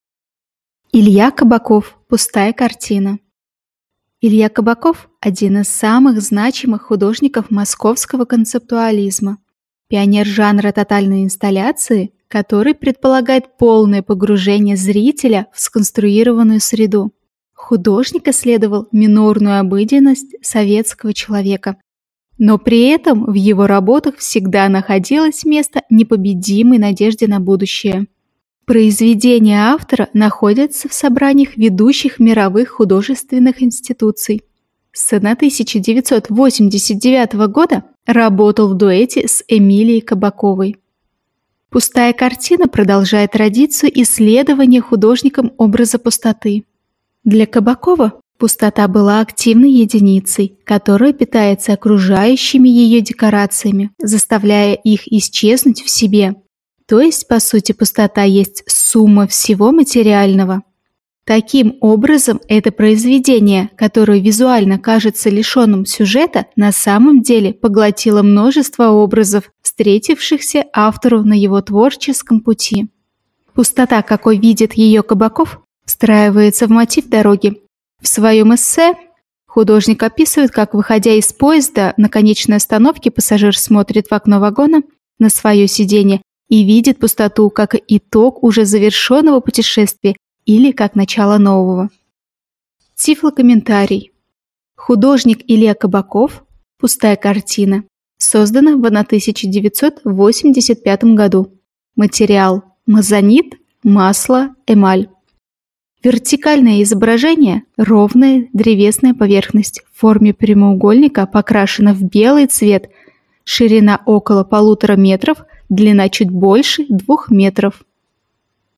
Тифлокомментарий к картине Ильи Кабакова "Пустая картина"